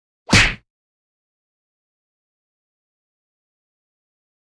赤手空拳击中肉体-YS070524.wav
通用动作/01人物/03武术动作类/空拳打斗/赤手空拳击中肉体-YS070524.wav
• 声道 立體聲 (2ch)